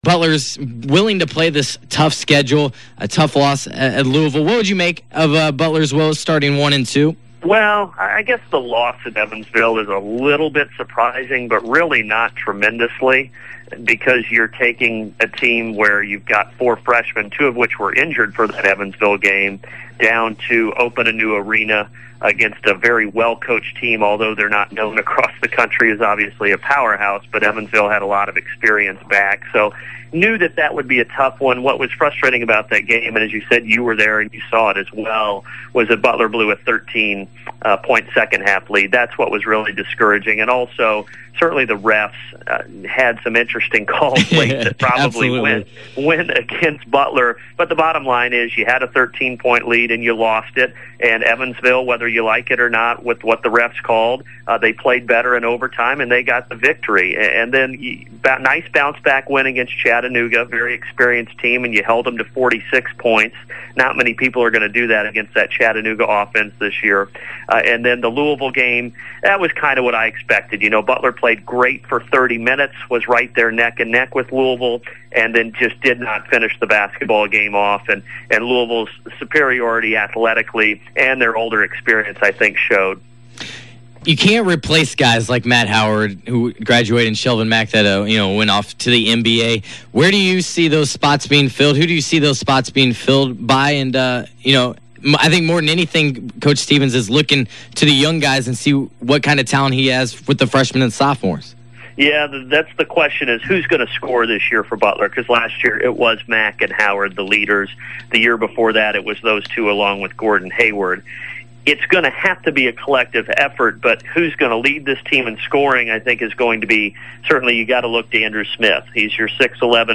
On my weekly radio show, I was joined by three unique and special guests to talk about hot topics.